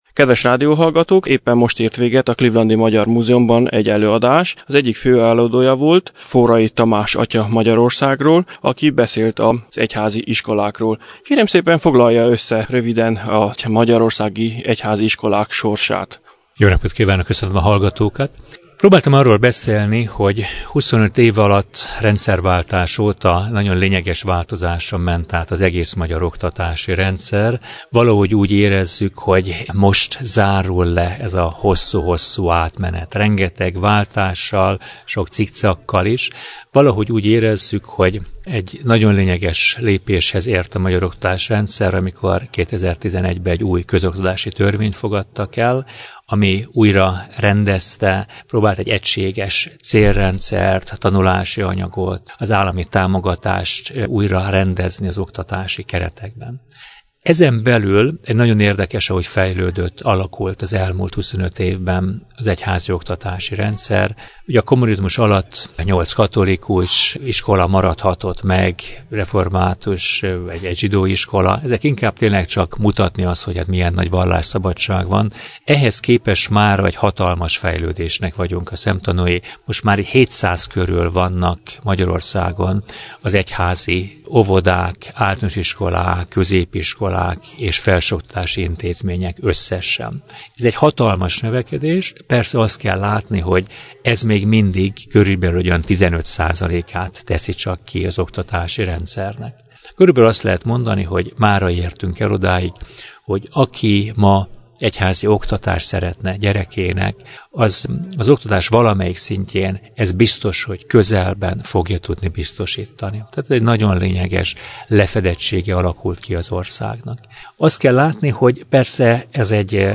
Az előadása végén kértem, hogy a rádióhallgatóknak is foglalja össze előadását: